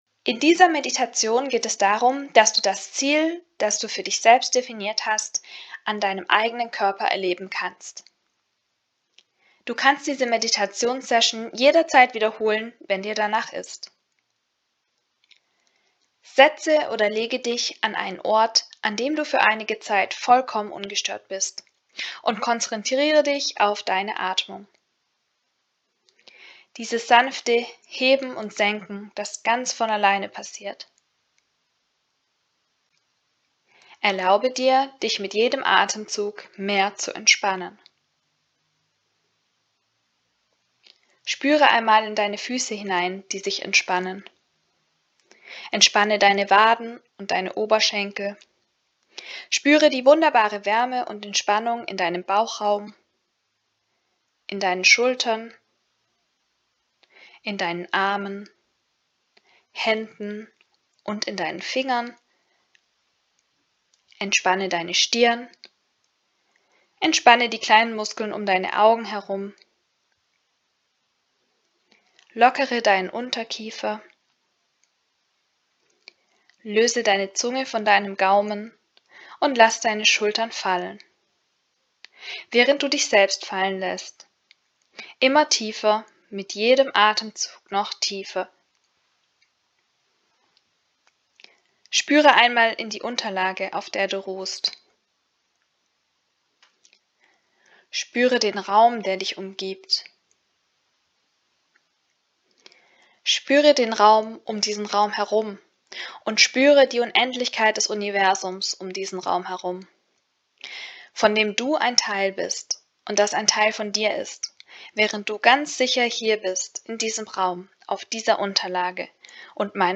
Meditation Dein Ziel erleben Anhören & Download Danke für deine Teilnahme am Webinar!